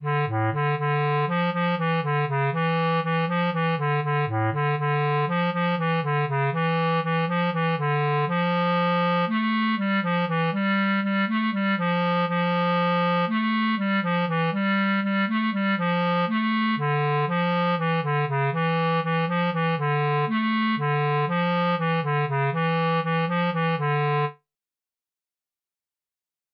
Jewish Folk Song (for Purim) - Chabad-Lubavitch Melody
D minor ♩= 120 bpm